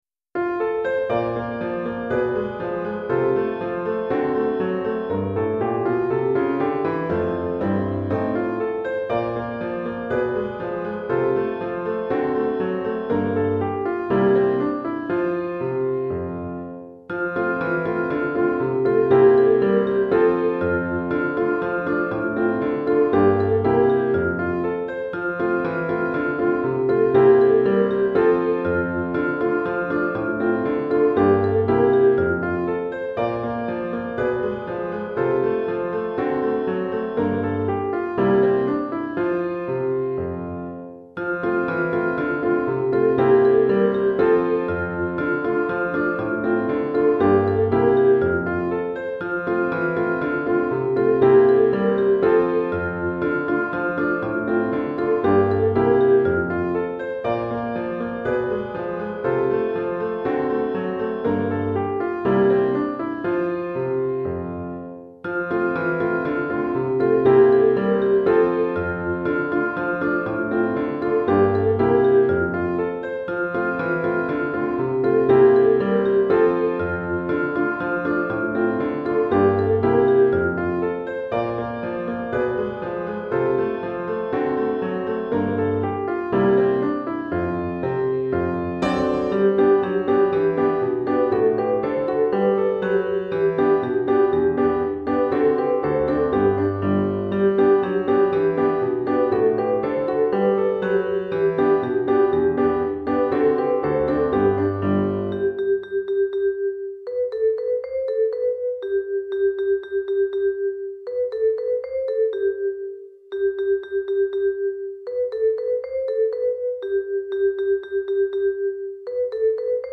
Chorale d'Enfants (7 ans) et Piano